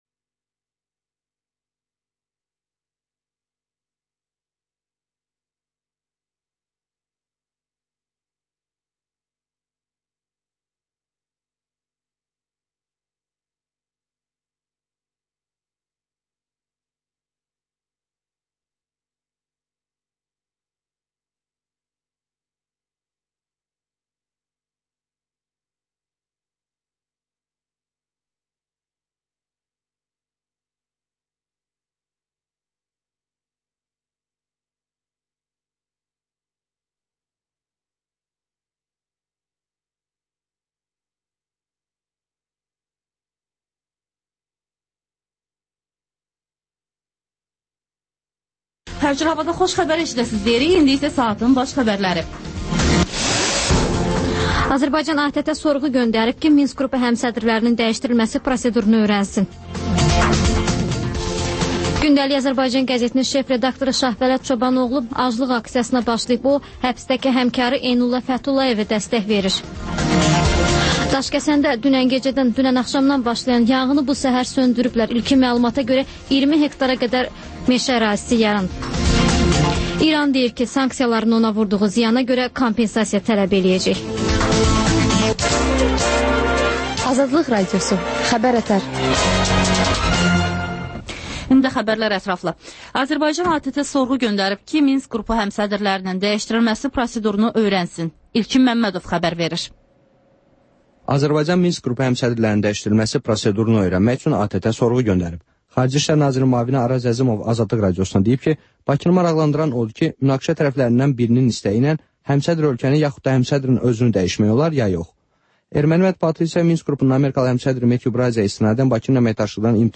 Xəbər-ətər: xəbərlər, müsahibələr, sonda 14-24: Gənclər üçün xüsusi veriliş